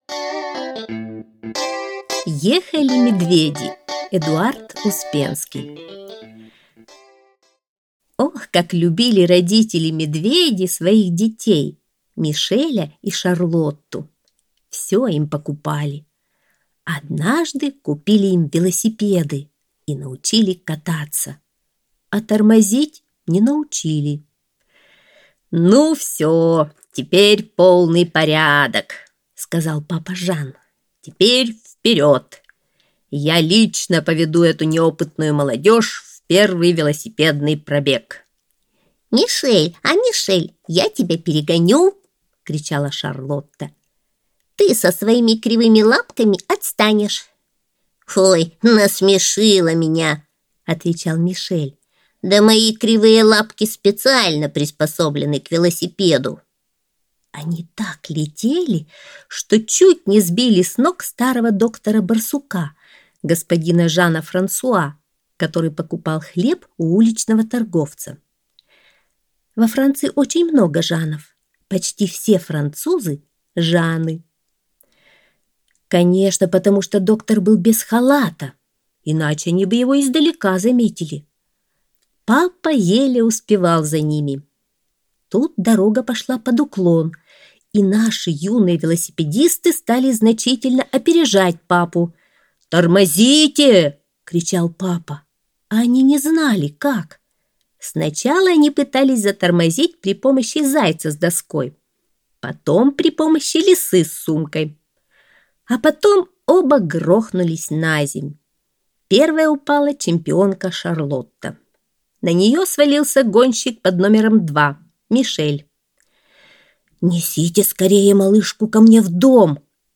Аудиосказка «Ехали медведи»